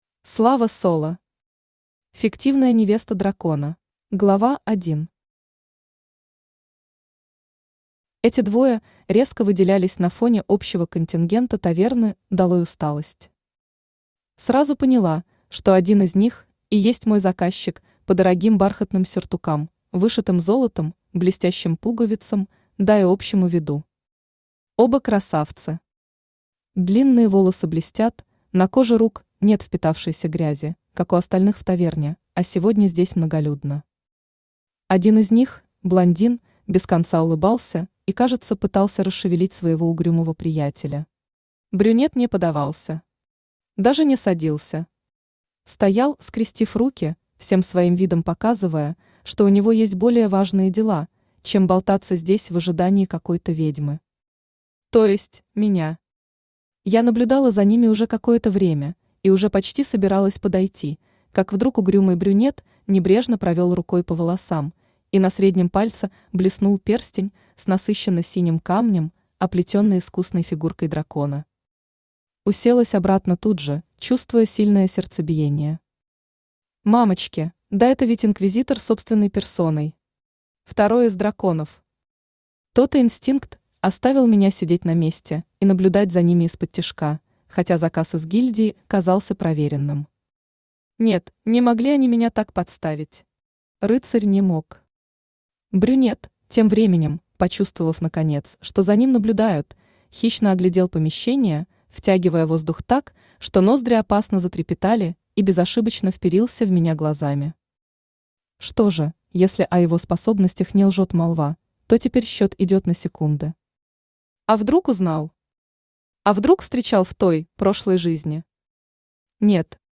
Аудиокнига Фиктивная невеста дракона | Библиотека аудиокниг
Aудиокнига Фиктивная невеста дракона Автор Слава Соло Читает аудиокнигу Авточтец ЛитРес.